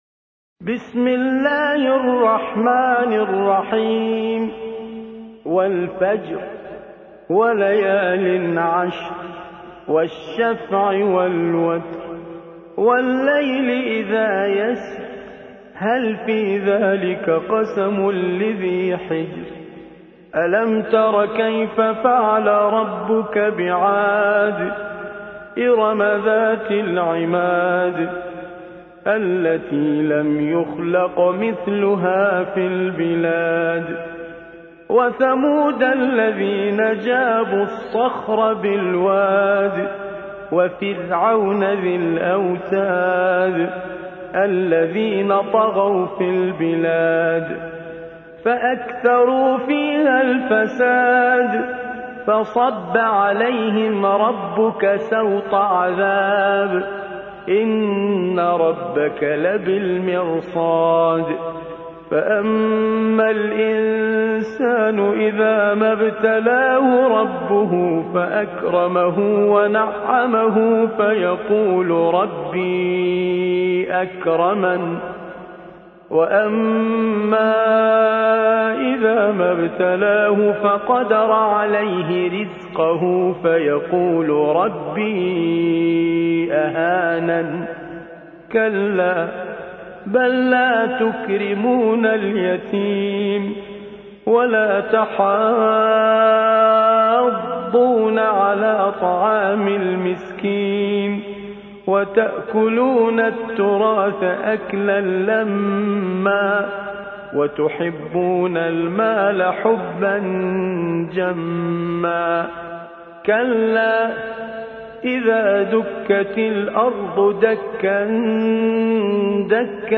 89. سورة الفجر / القارئ